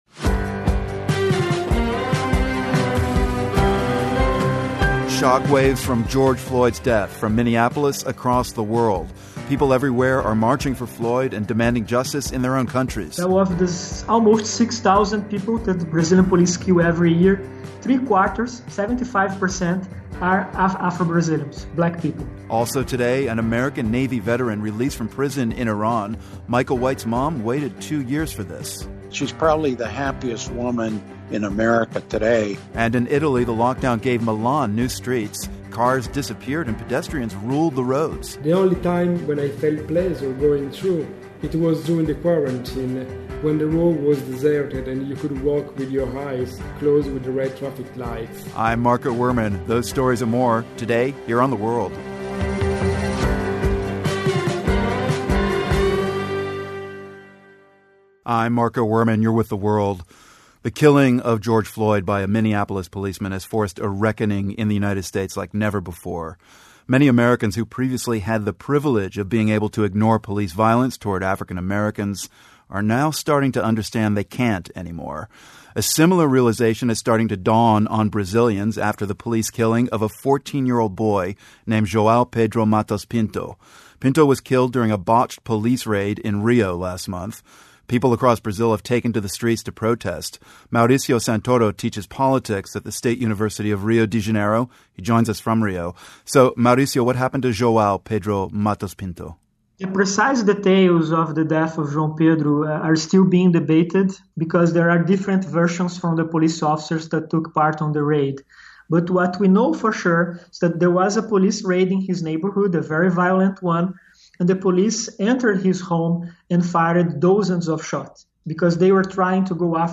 We take you to a busy street in Milan to hear how people are using new bike lanes and socially-distanced sidewalks.